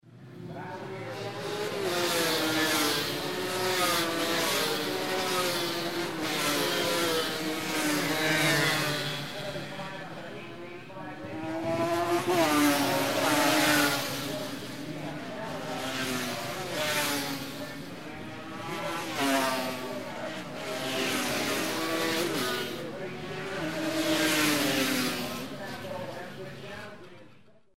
Звуки мотокросса